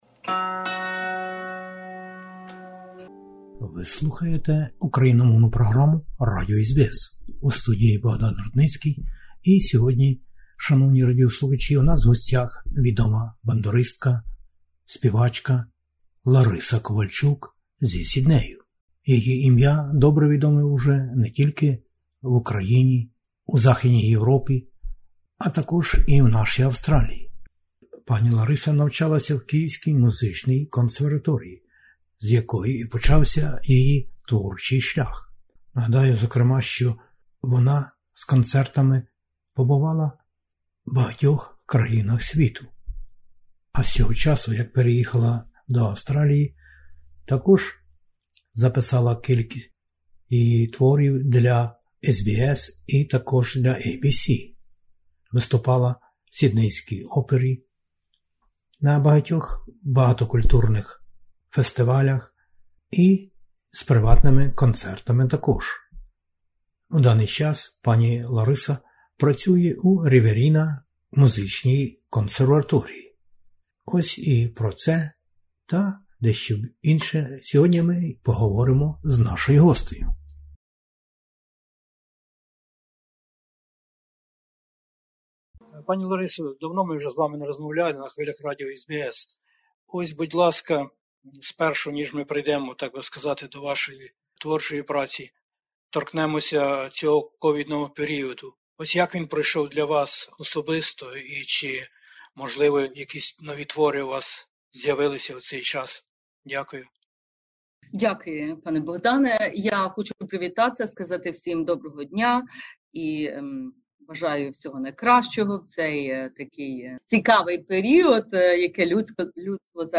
яка прибула сюди із України і уже довший час захоплює своїм своєрідним сопрано у поєднанні з ніжними звуками бандури.
Отож, тепер відома мисткиня у нас в гостях знову...